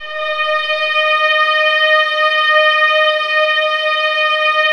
VIOLINS FN5.wav